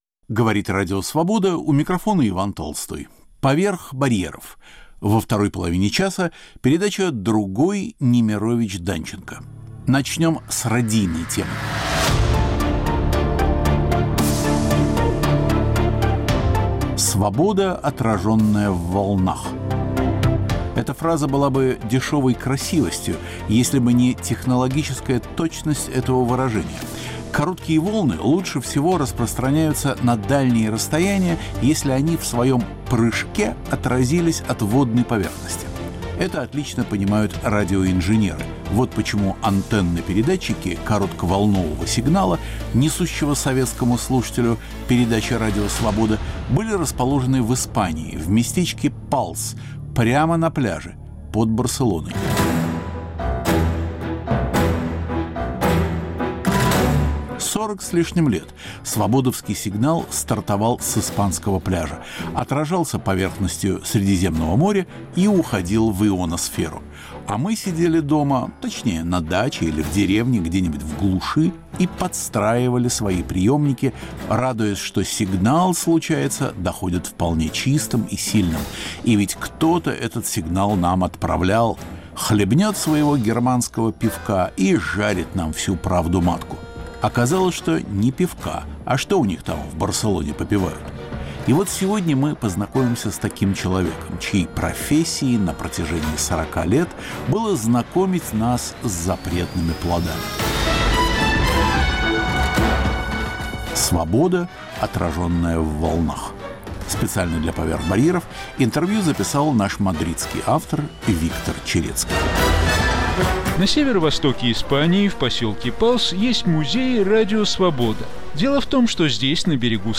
Интервью с испанским инженером, ветераном "Свободы".